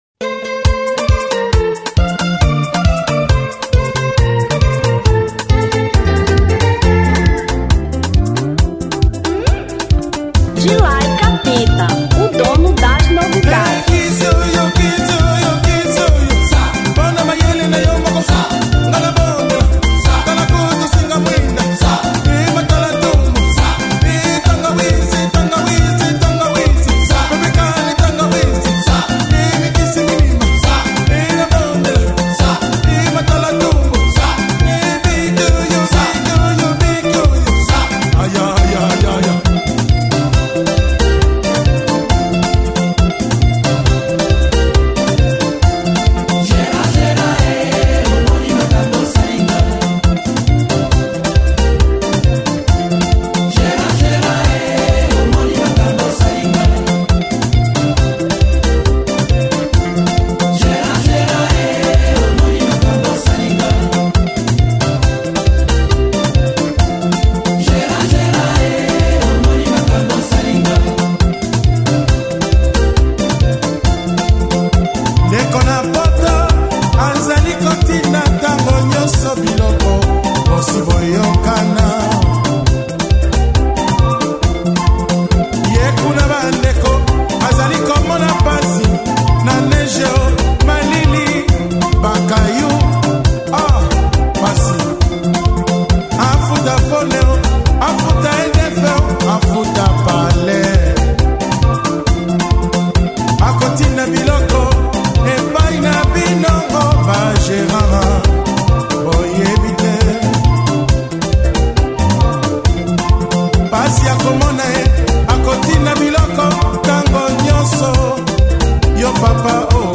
Rumba 1992